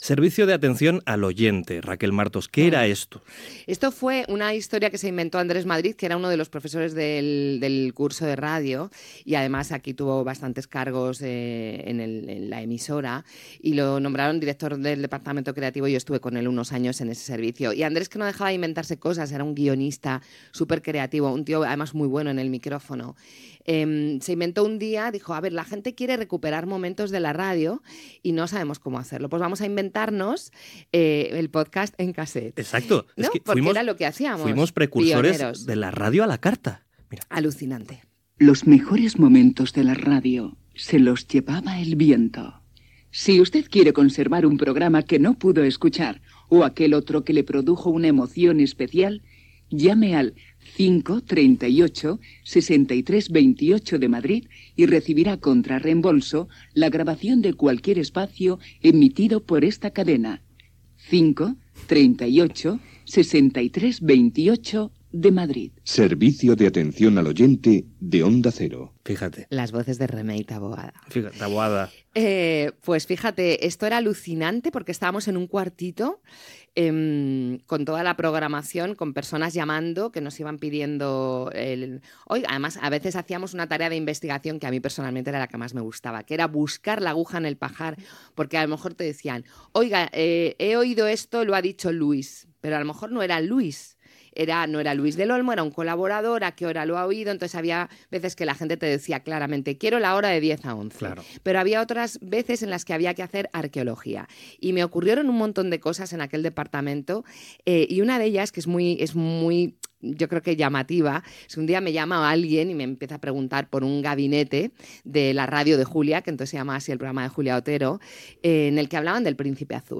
Conversa amb Raquel Martos sobre el Servicio de Atención al Oyente de Onda Cero
FM